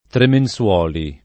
[ tremen SU0 li ]